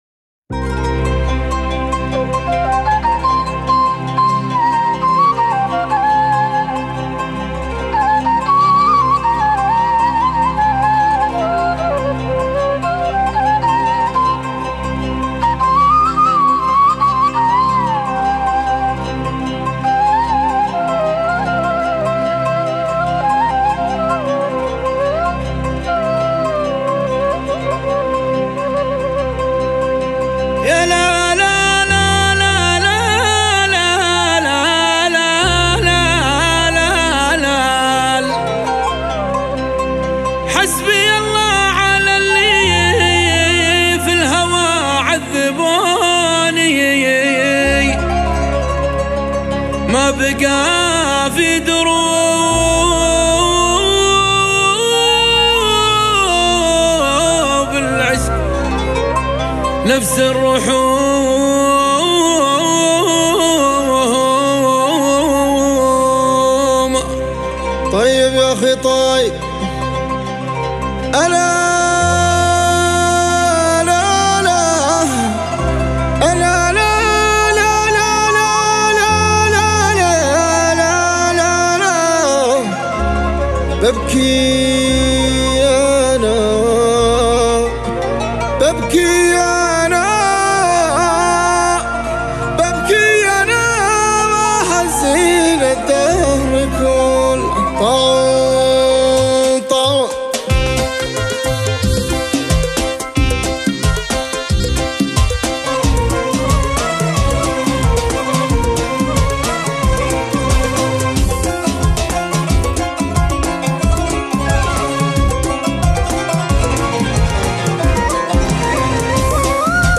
شيلات دويتو